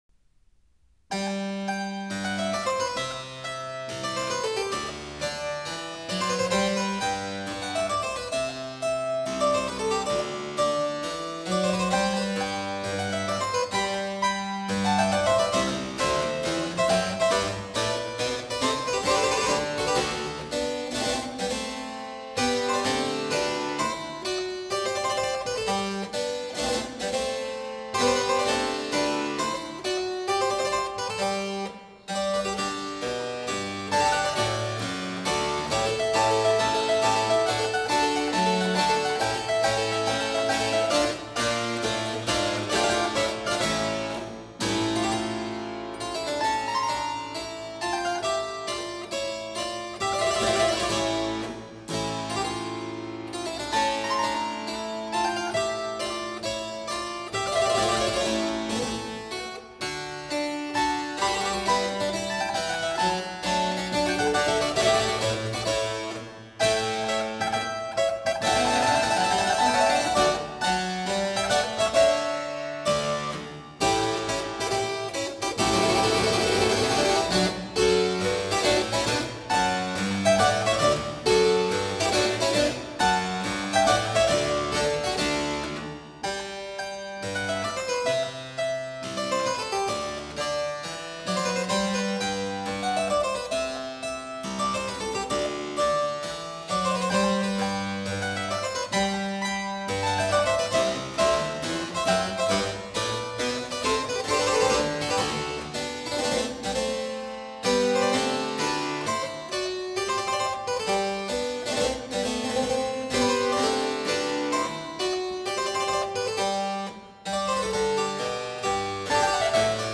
他的奏鸣曲在旋律、和声、织体、节奏、装饰等方面，均深受多梅尼科·斯卡拉蒂的影响，体现出典型的西班牙民间音乐与宫廷音乐的特征。
Concerto No. 3 in G major ,- Kenneth Gilbert, Trevor Pinnock 第一乐章--------Andantino： 第二乐章--------Minue： Concerto No. 3 in G major - Minue